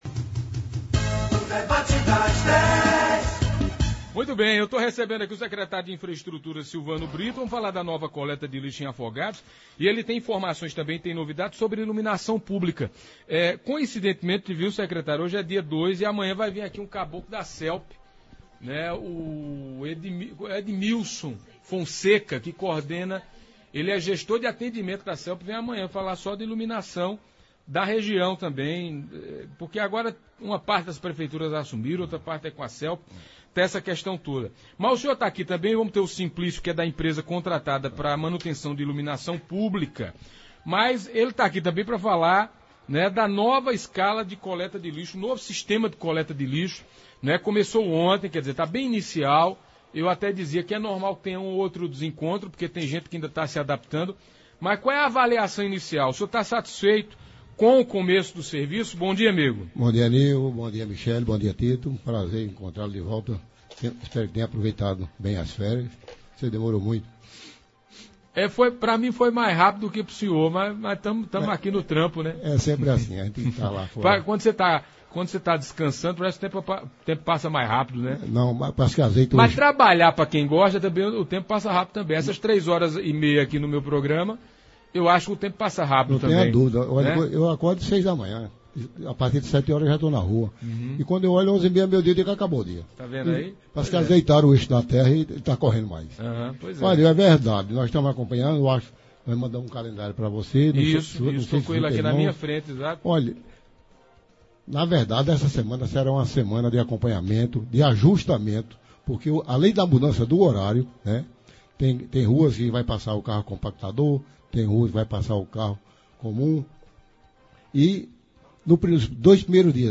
Coleta de lixo e iluminação pública foram debatidos na Pajeú – Rádio Pajeú
No Debate das Dez desta terça-feira (02) na Rádio Pajeú, o secretário de Obras e Infraestrutura de Afogados da Ingazeira Silvano Queiroz, falou sobre a nova escala de coleta de lixo que passou a funcionar no município desde a última segunda-feira (01), com a chegada do caminhão compactador de lixo, que foi entregue no sábado (30).
Falou ainda que as caçambas continuarão sendo usadas na coleta em alguns pontos da cidade. Também falou sobre as demandas de sua pasta e respondeu questionamentos dos ouvintes e internautas da Pajeú.